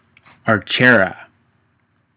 Archerra   \’är-cher-ä\
How to pronounce Archerra